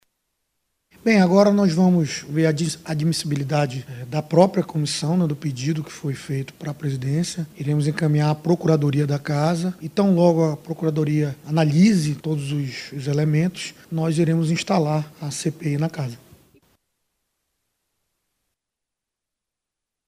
Sonora-Caio-Andre-–-presidente-da-CMM.mp3